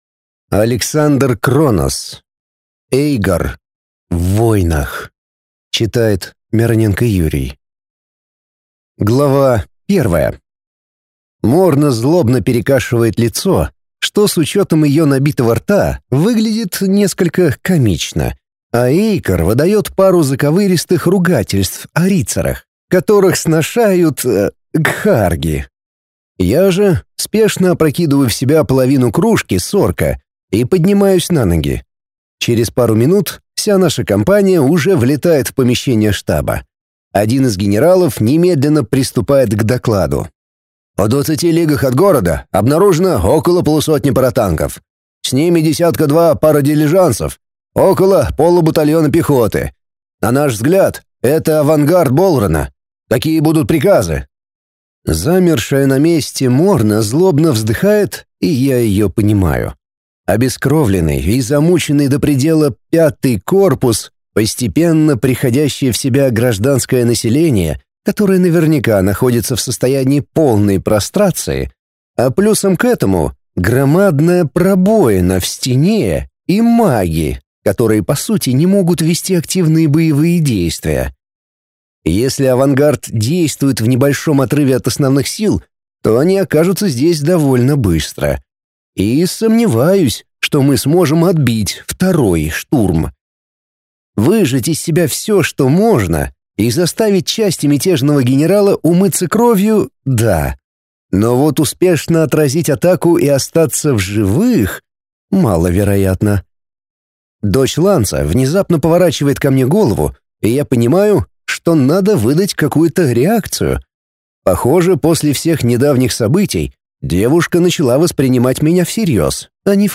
Аудиокнига Эйгор. В войнах | Библиотека аудиокниг